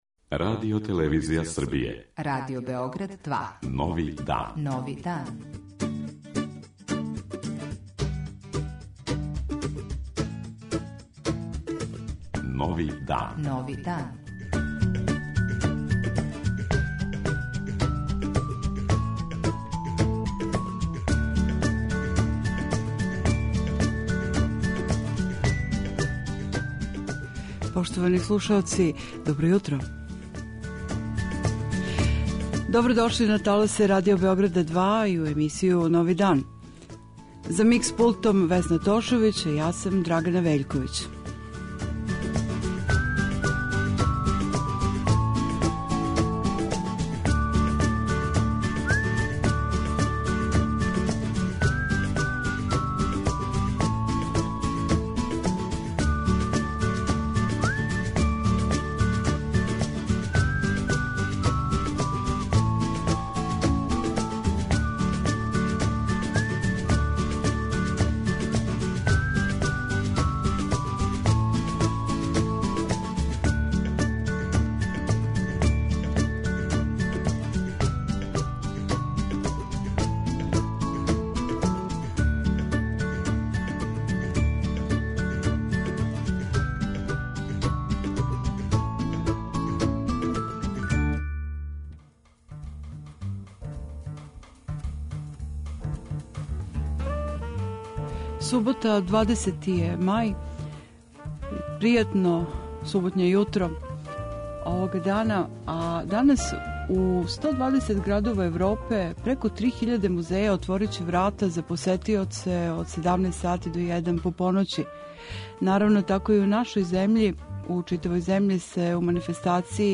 Јутарњи магазин